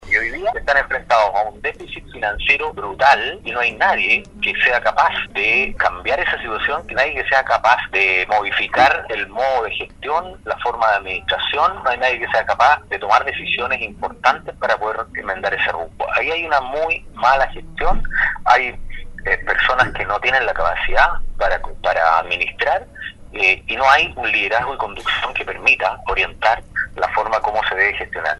En conversación con Radio Sago, el ex parlamentario, señaló que lo más delicado del problema es que se ha hecho un mal uso de los recursos, que sólo profundizaron el déficit que hoy mantiene el municipio en el área de Educación. Agrega Recondo que en general la Municipalidad de Puerto Varas está inmersa en un déficit financiero enorme que no resiste análisis, siendo muy difícil estabilizarse en un panorama que cada vez es peor.